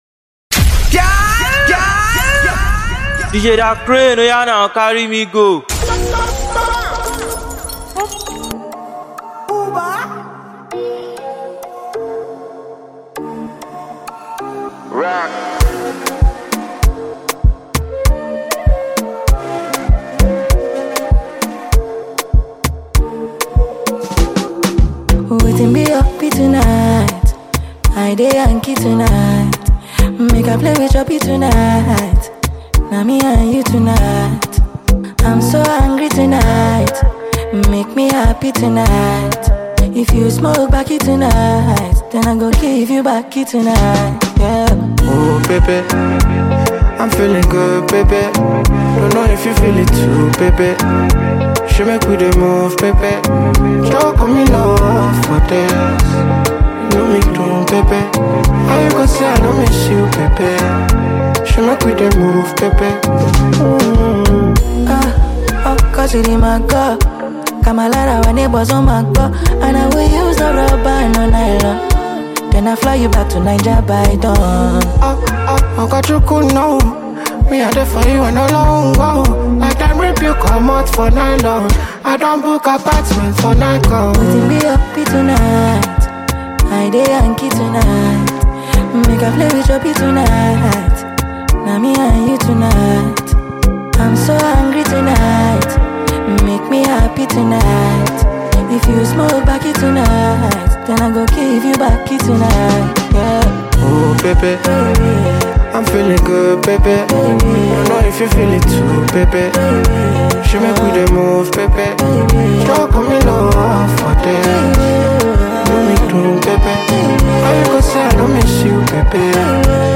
an electrifying mix
featuring the best of Afrobeats and rhythms from Nigeria